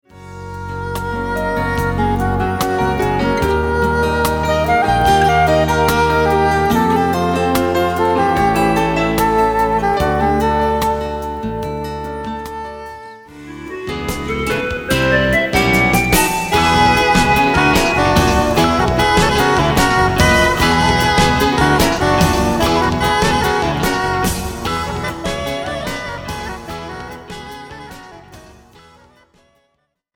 percussion
drums